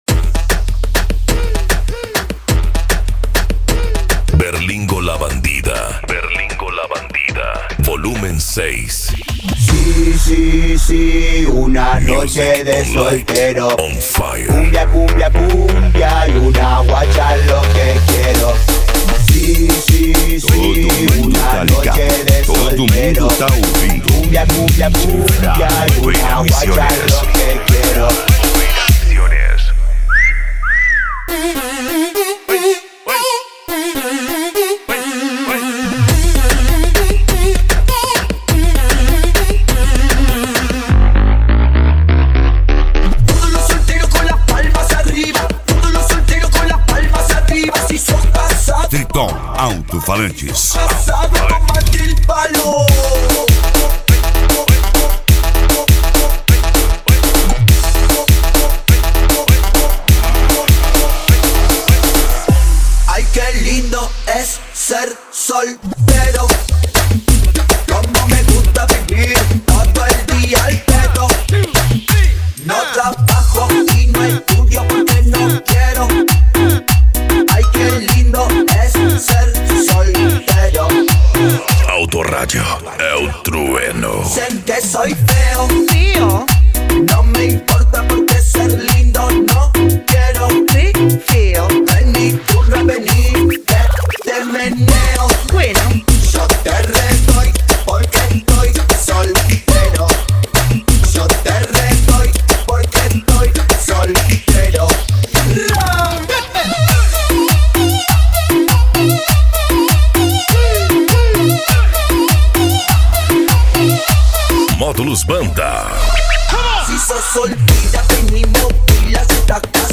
Funk
Mega Funk
Remix